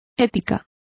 Complete with pronunciation of the translation of ethic.